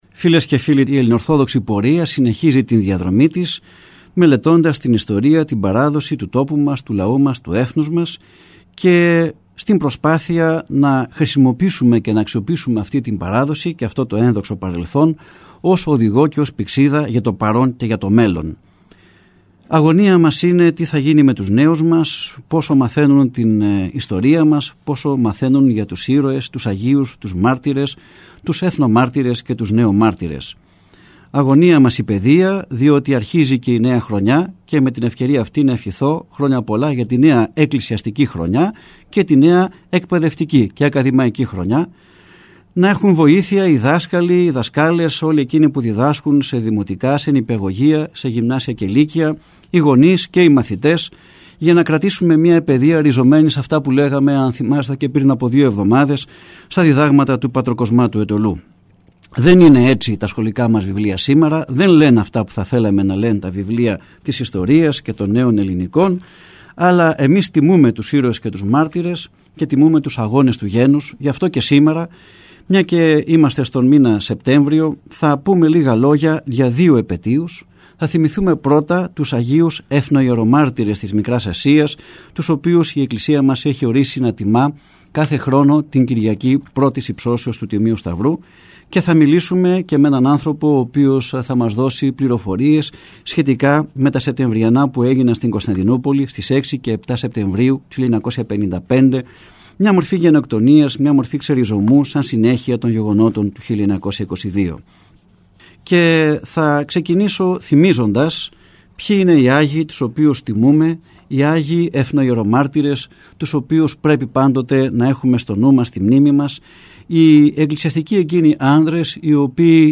Ακούστε στην συνέχεια, ηχογραφημένα αποσπάσματα της ραδιοφωνικής εκπομπής «Ελληνορθόδοξη πορεία», που μεταδόθηκε από τον ραδιοσταθμό της Πειραϊκής Εκκλησίας, την Κυριακή 12 Σεπτεμβρίου του 2010.